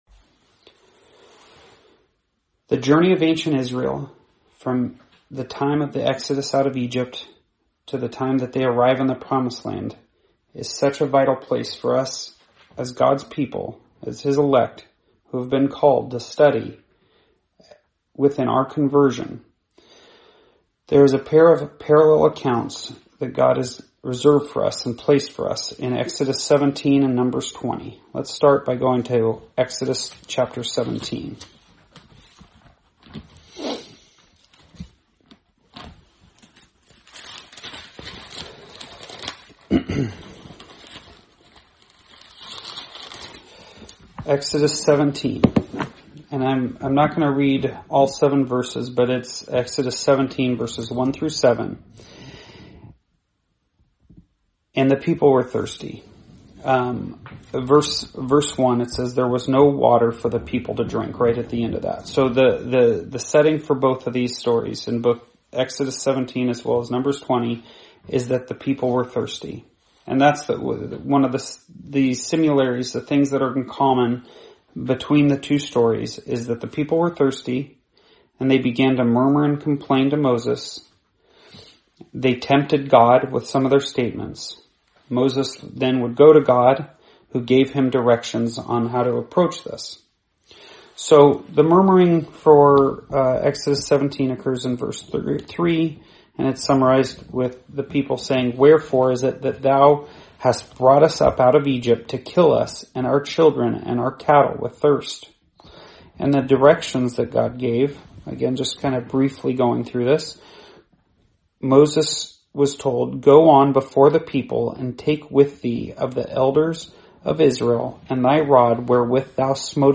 Sermonette